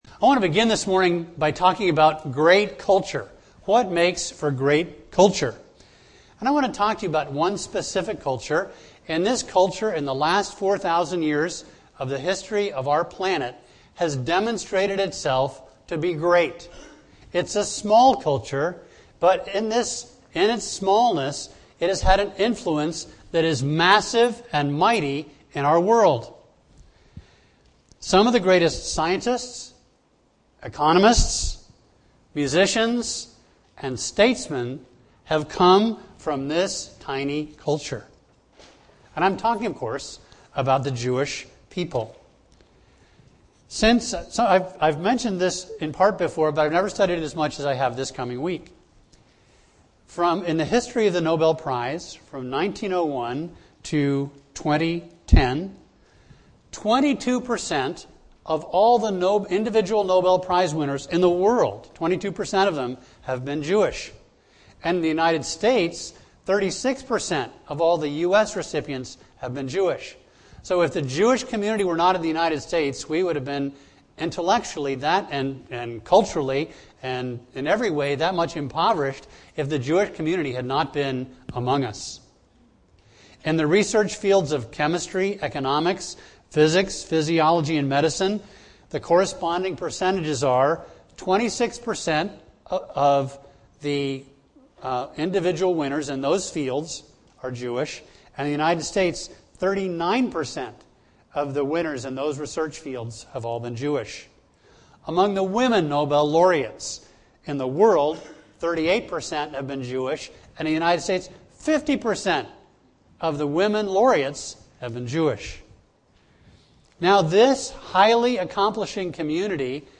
A message from the series "10 Commandments."